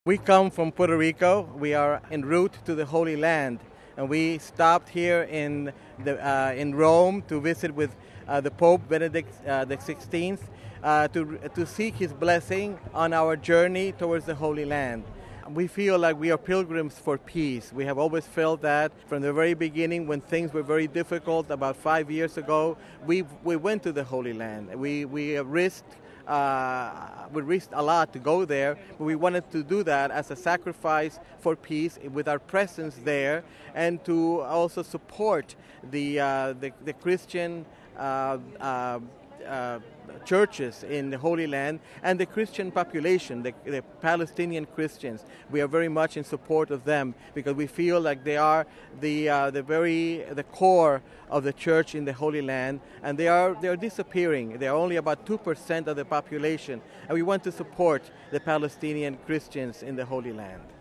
He tells us more about their pilgrimage of peace.